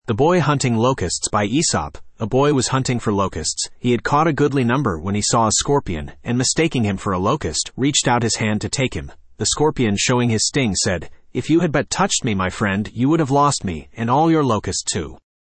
The Boy Hunting Locusts Spoken Ink « Æsop's Fables The Boy Hunting Locusts Studio (Male) Download MP3 A BOY was hunting for locusts.
the-boy-hunting-locusts-en-US-Studio-M-cb14e6b5.mp3